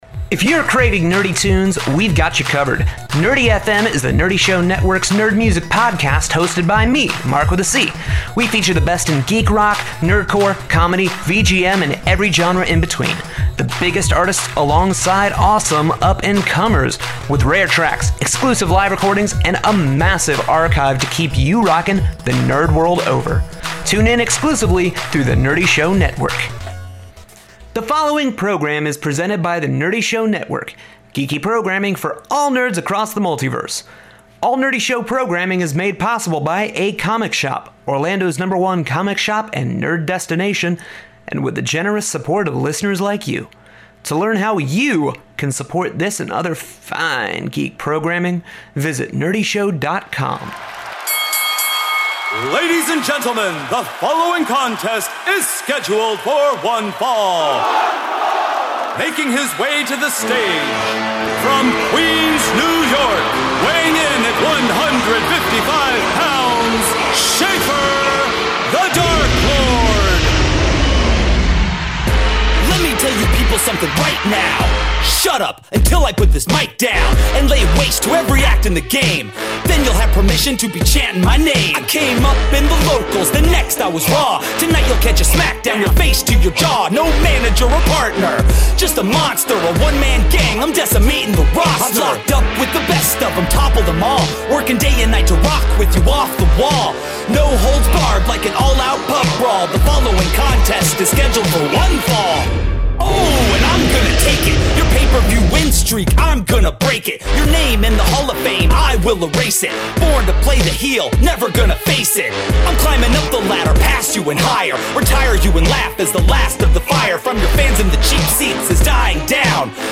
This episode leans heavy on the nerdcore hi-hop side of things, but there's still a little bit of chip, comedy and geek rock to go around.